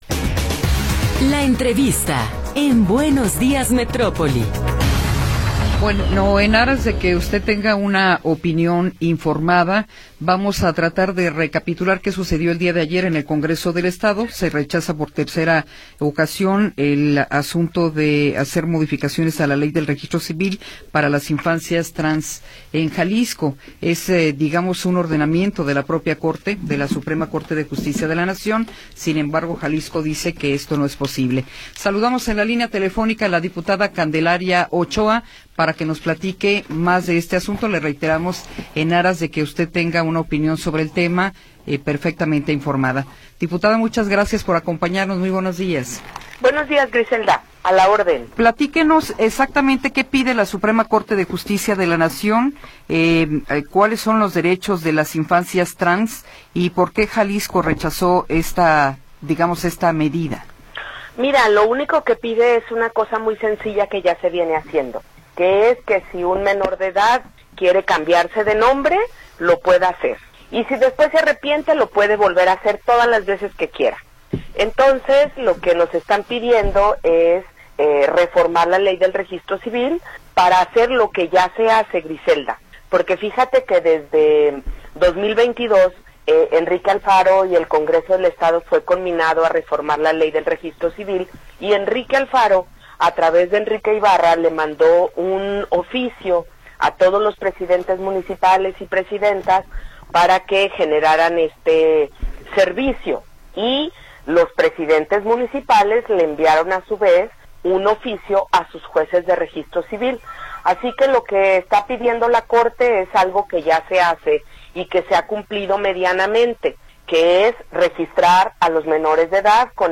Entrevista con Candelaria Ochoa Ávalos